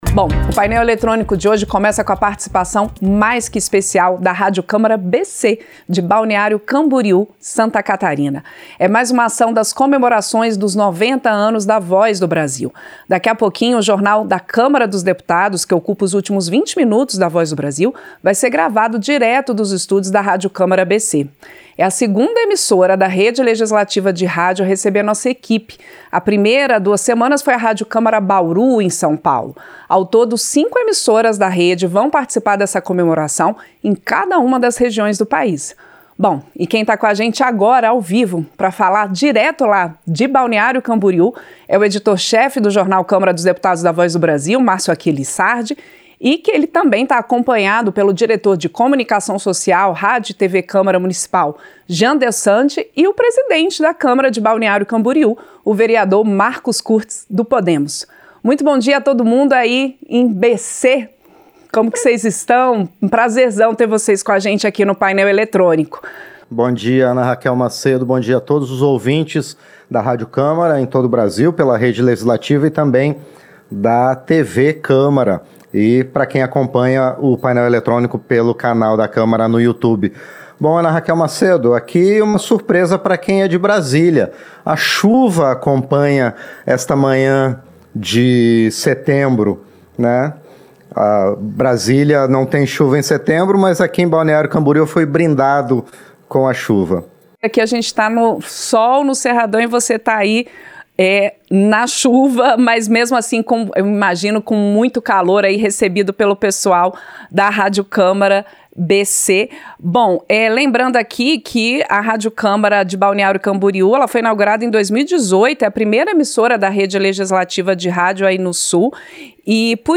Gravação itinerante de “A Voz do Brasil” chega a Balneário Camboriú (SC); ação integra comemorações dos 90 anos do programa - Rádio Câmara
Como parte das comemorações dos 90 anos do programa “A Voz do Brasil”, o Jornal da Câmara dos Deputados, que ocupa os últimos 20 minutos do histórico noticiário brasileiro, vai ser gravado nos estúdios de cinco emissoras da Rede Legislativa de Rádio e TV.